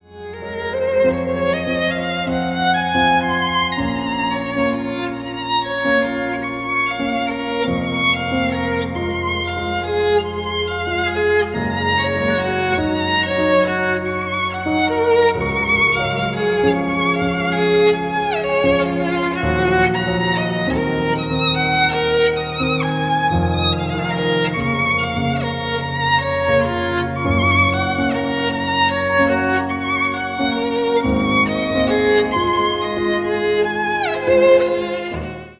Stereo
грустные без слов